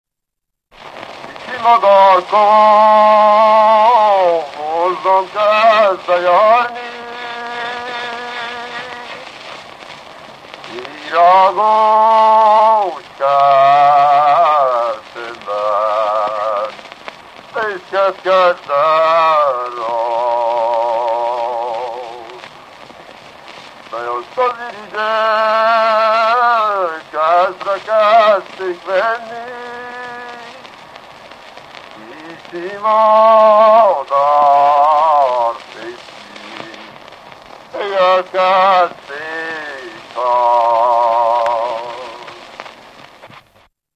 Erdély - Csík vm. - Doboj (Kászonimpér)
ének
Műfaj: Keserves
Gyűjtő: Kodály Zoltán
Stílus: 1.1. Ereszkedő kvintváltó pentaton dallamok